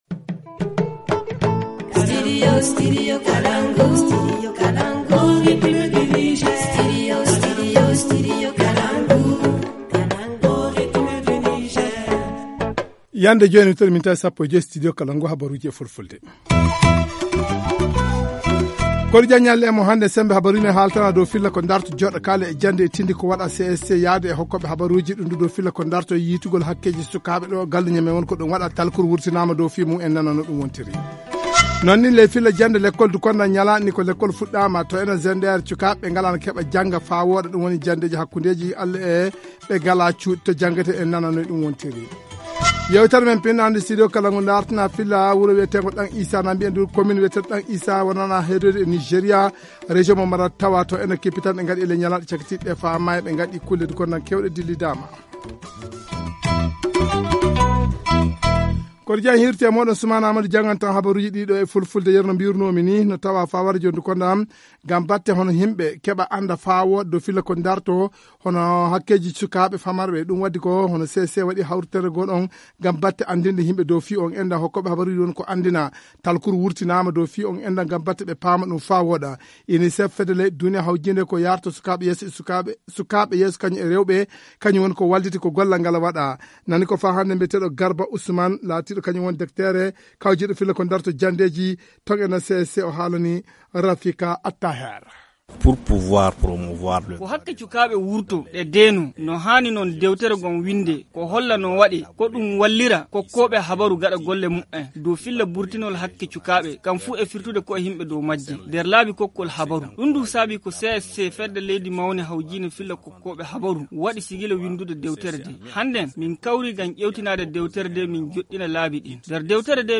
Le journal du 18 octobre 2019 - Studio Kalangou - Au rythme du Niger